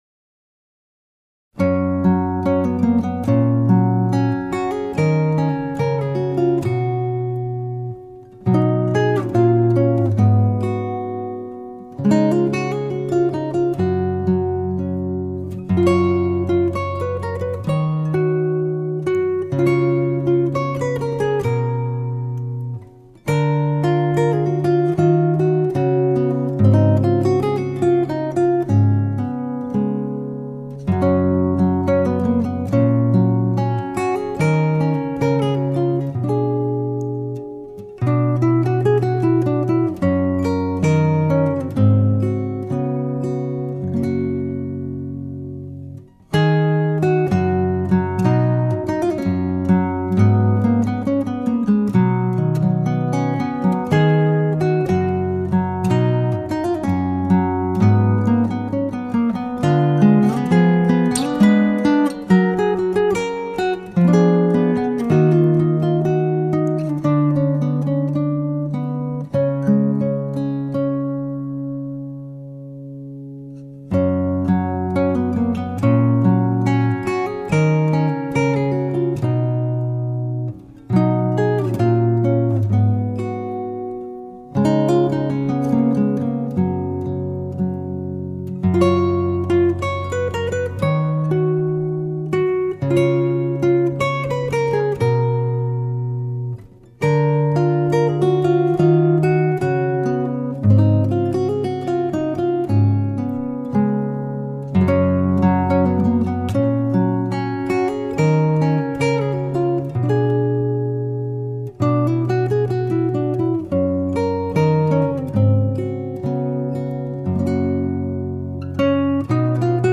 موسیقی بی کلام , آرامش بخش , عصر جدید , گیتار
موسیقی بی کلام گیتار